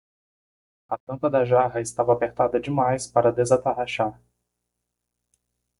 Pronounced as (IPA) /ˈʒa.ʁɐ/